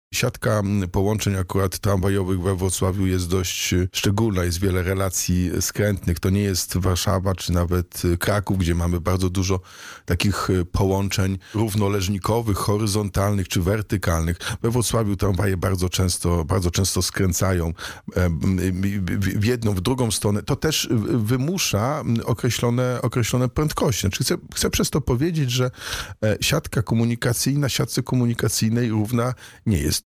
– Staram się szukać przebaczenia i wybaczać nawet tym, którzy czynią mi źle – powiedział w rozmowie z Radiem Rodzina prezydent Wrocławia, Jacek Sutryk.